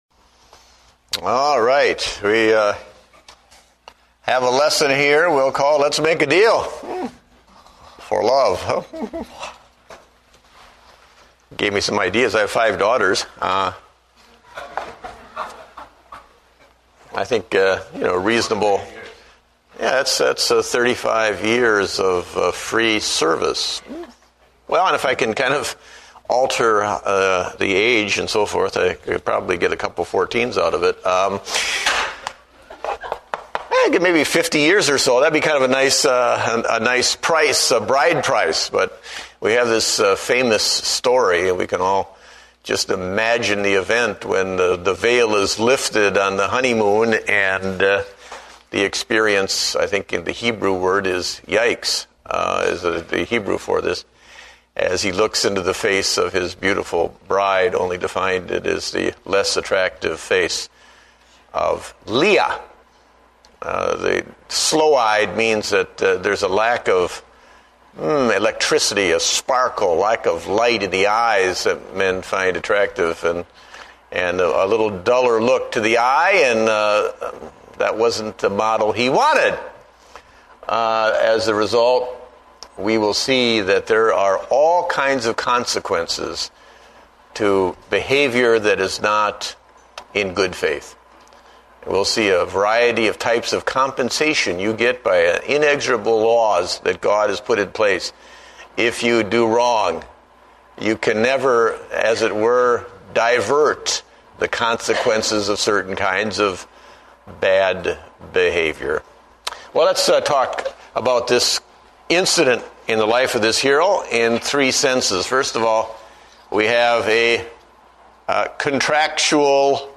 Date: April 19, 2009 (Adult Sunday School)